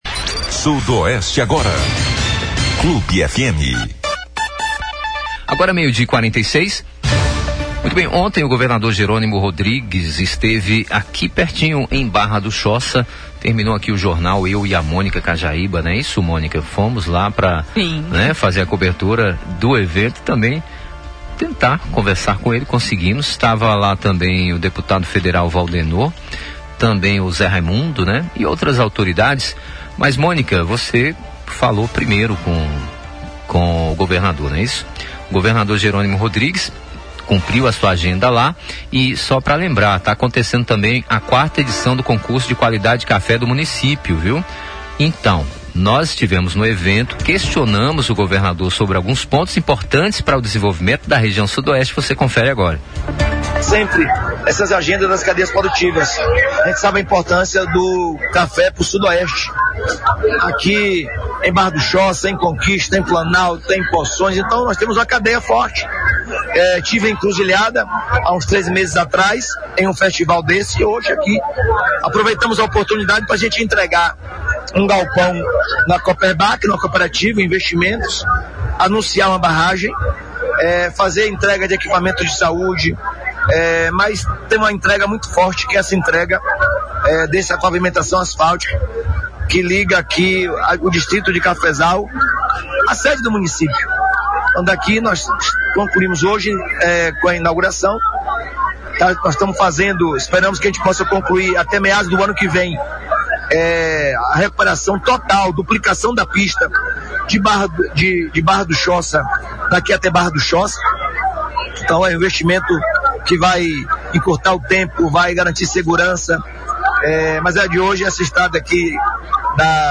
Entrevista à Rádio Clube de Conquista | Jerônimo fala sobre Saúde com prazo para inaugurar a Barragem do Catolé